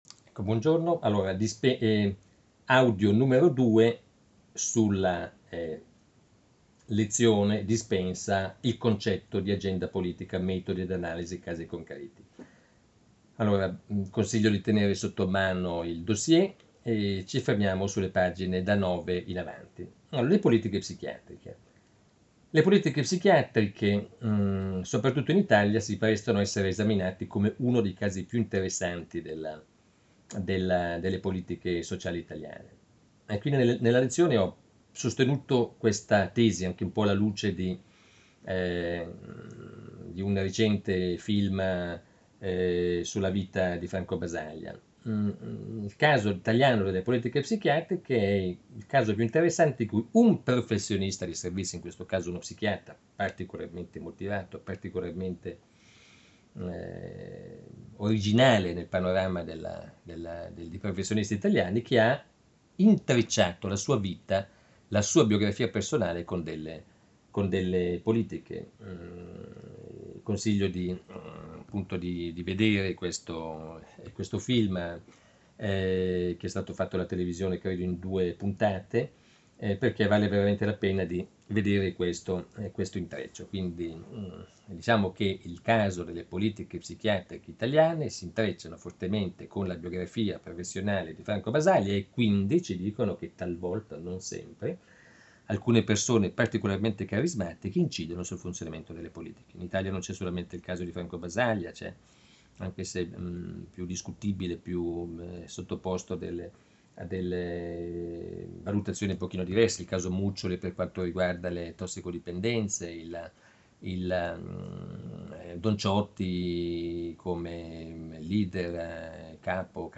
Il concetto di “agenda politica” e Analisi di alcune politiche sociali. 4° Lezione